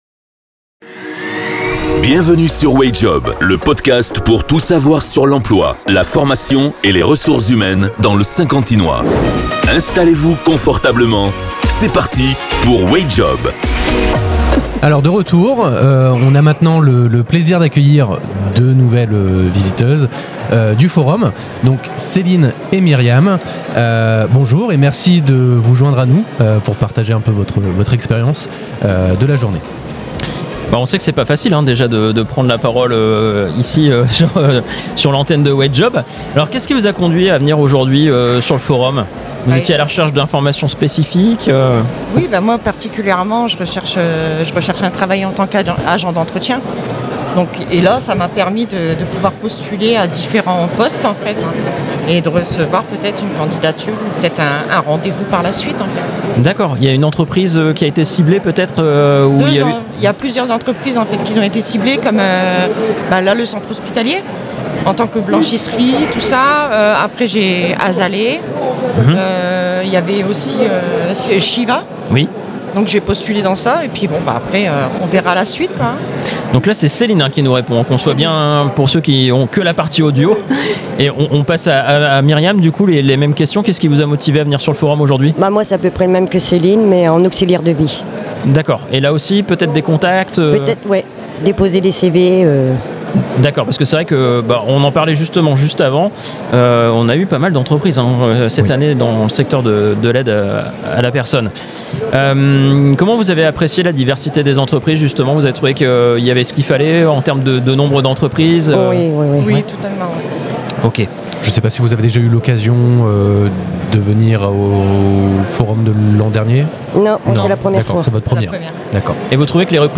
deux visiteuses du salon qui partagent leurs impressions sur leur première participation à un forum emploi.